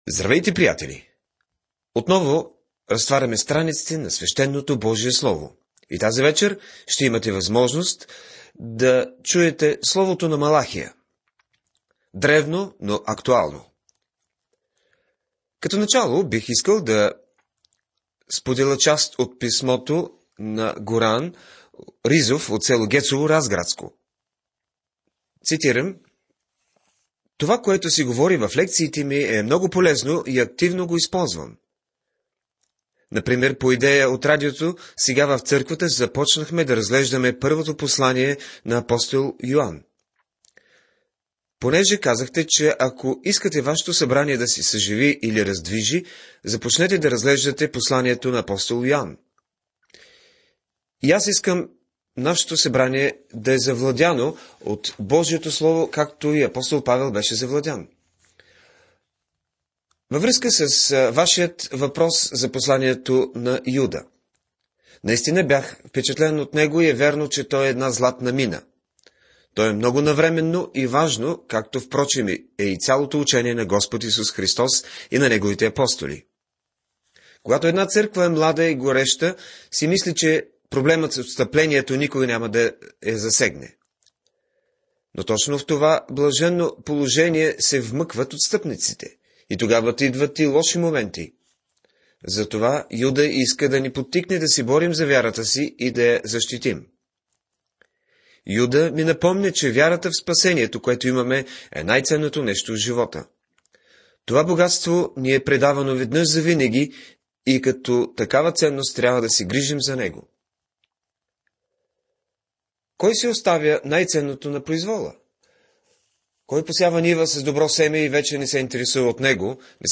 Тя представлява ежедневна 30-минутна радио програма, в която слушателят систематично преминава през съдържанието цялата Библия.